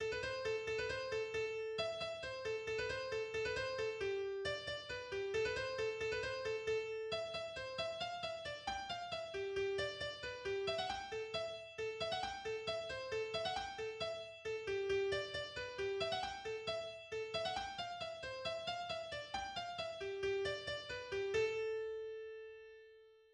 We see this in double-tonic tunes such as "Donald MacGillavry" (notes: A to G in bar 4 below).[1] Shifting is more emphatic than chord changes (chords: Am-G), but not as emphatic as modulations (keys: A minor to G major):[1]